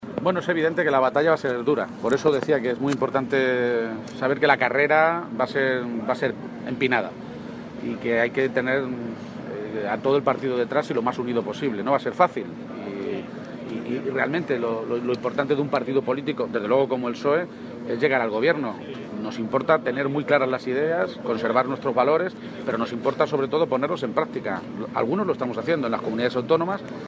Así lo reconocía a su llegada al Comité Federal del PSOE celebrado esta mañana en Aranjuez.
Cortes de audio de la rueda de prensa